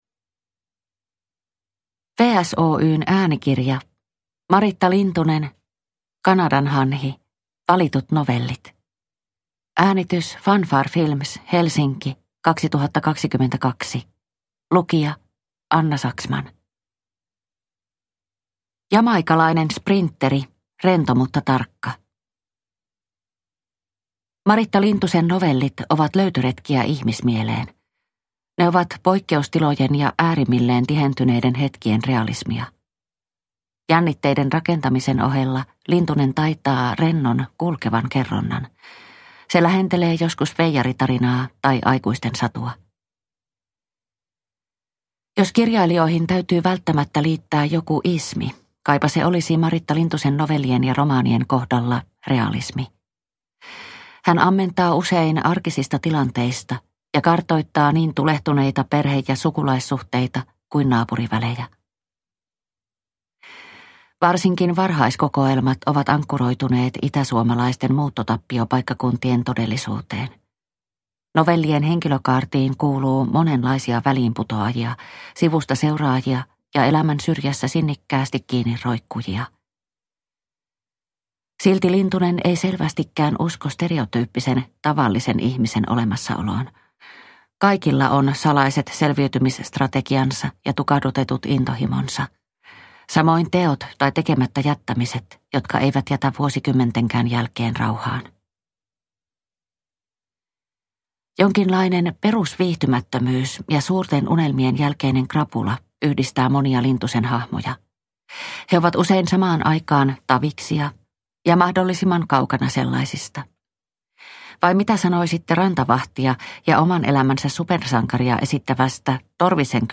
Kanadanhanhi. Valitut novellit – Ljudbok – Laddas ner